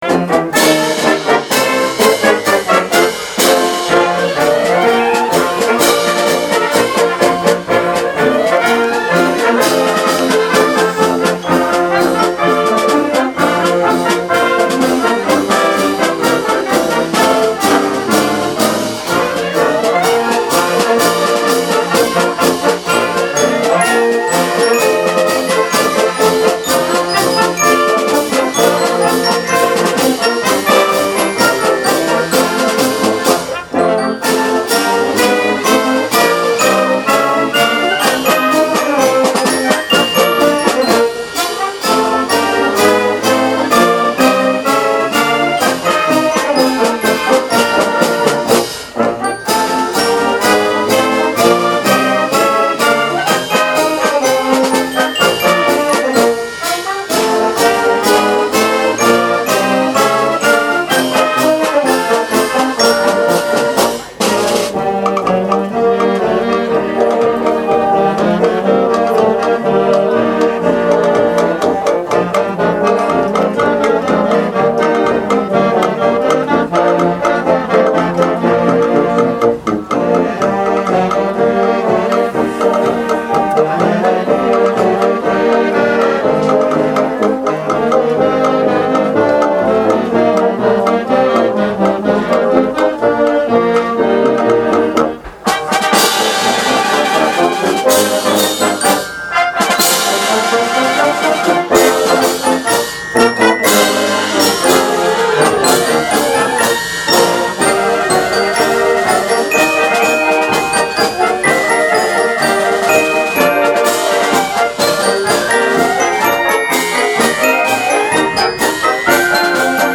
Azalea Winds, based in Valdosta, is the first community band in south Georgia.  The band played its second gig at the Horse Creek Winery in Sparks to an enthusiastic crowd.
The performance was a celebration of American music.